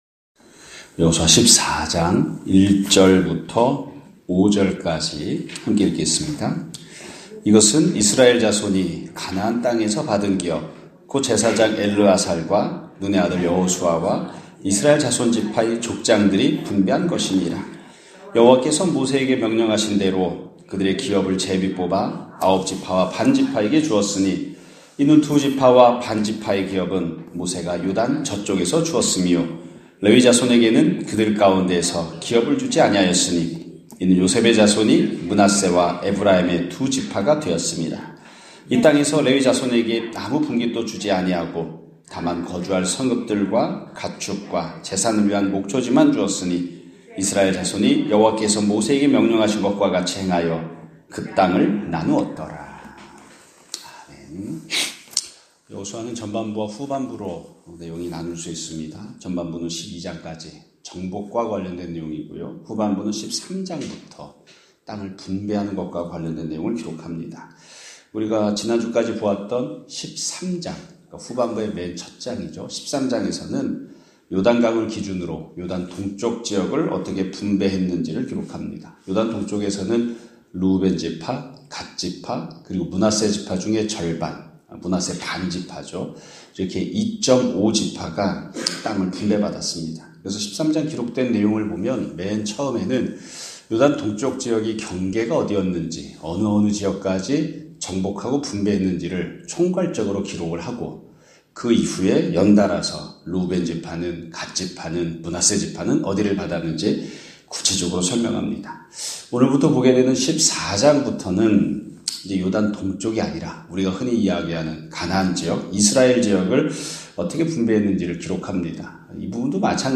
2024년 11월 25일(월요일) <아침예배> 설교입니다.